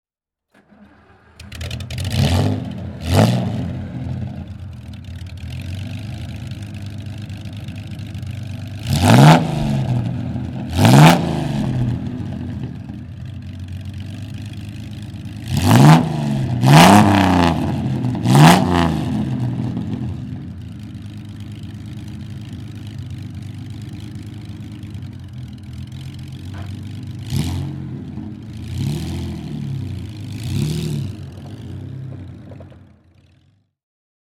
MG NA/NE (1934) - Starten und Leerlauf